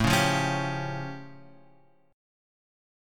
A Augmented 7th